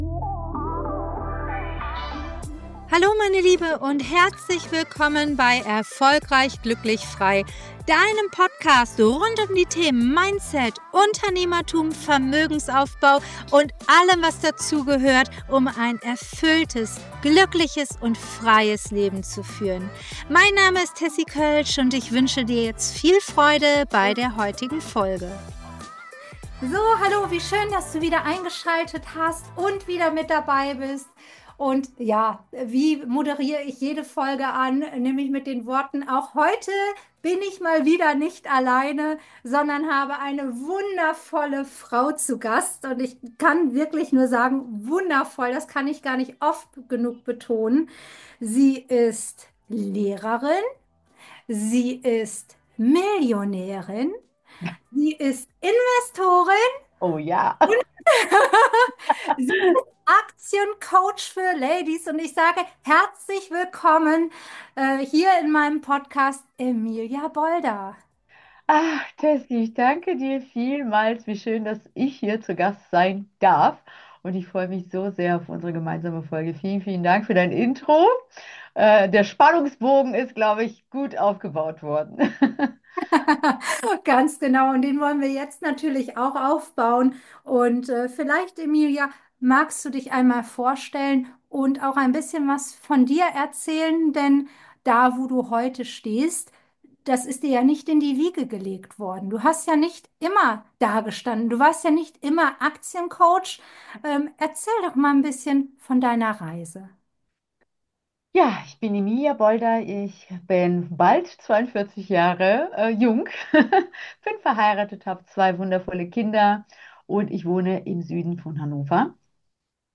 #11 Investiere dich frei – Interview